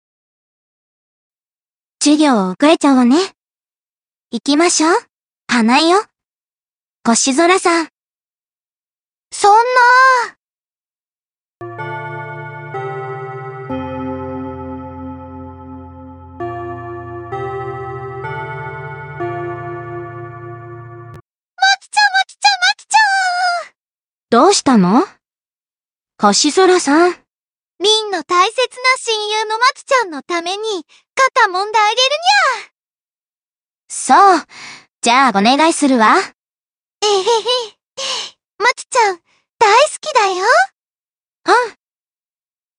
注意：過去のラ！板ｓｓで自分の好きなものを、最近はやりの中華ＡＩツールに読ませてみました。
暗い声色が少ないから悲しんでてもテンション高いな笑
音程の調整はできないんだよ